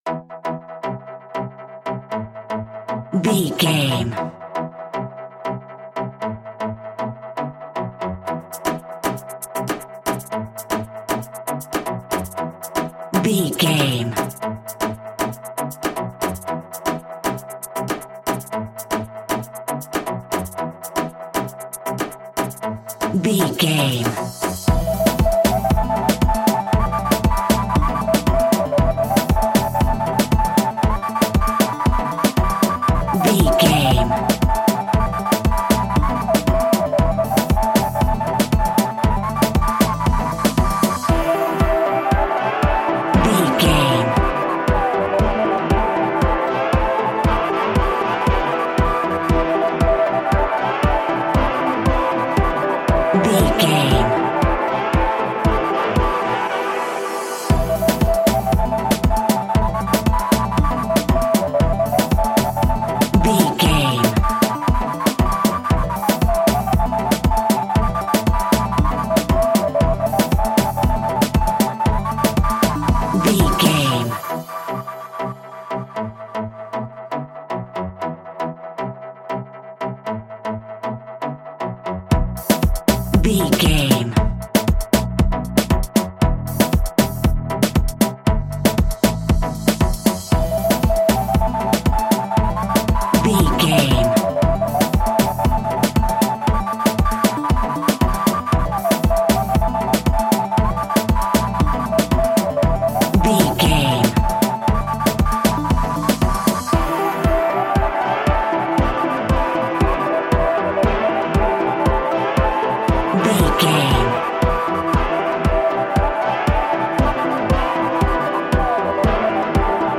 Classic reggae music with that skank bounce reggae feeling.
Aeolian/Minor
dub
instrumentals
laid back
chilled
off beat
drums
skank guitar
hammond organ
percussion
horns